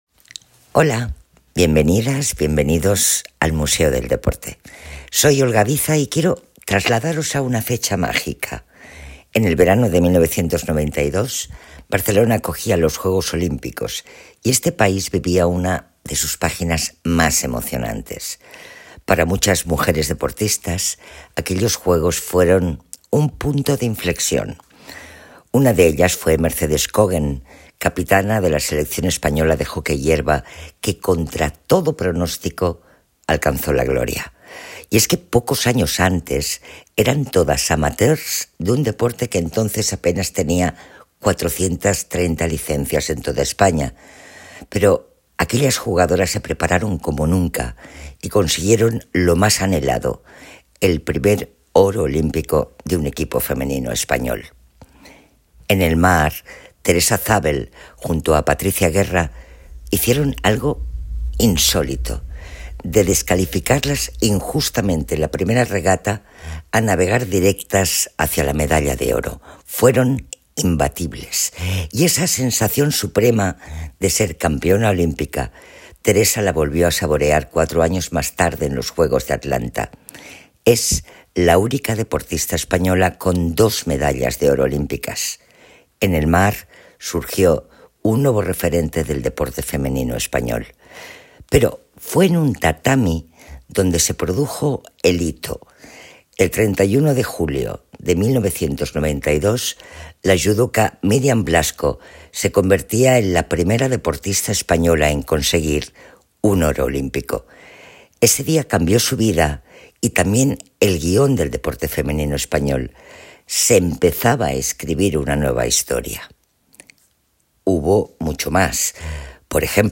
Escucha a Olga Viza hablar de los Juegos de Barcelona ’92